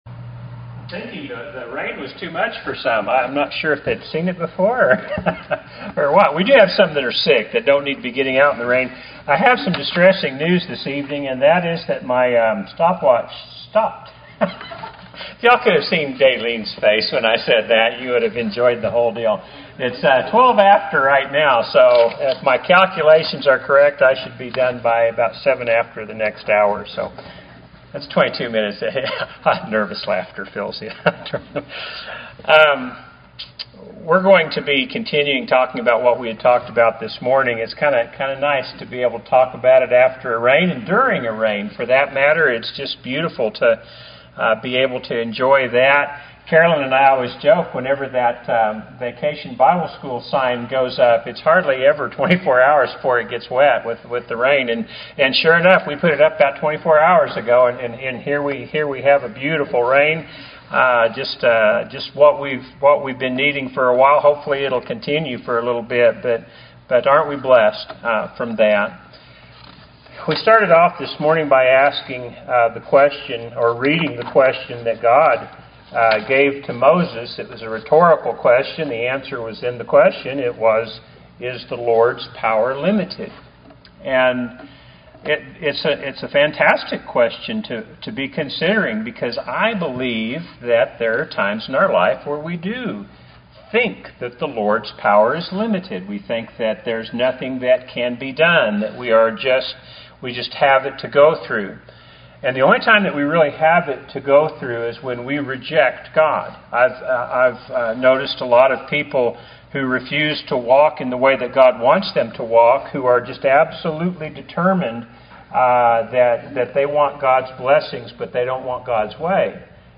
Happy Church of Christ Listen to Sermons